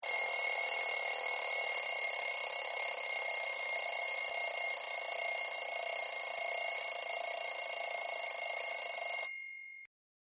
NGM_Alarm_clock.ogg